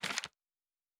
pgs/Assets/Audio/Fantasy Interface Sounds/Page 07.wav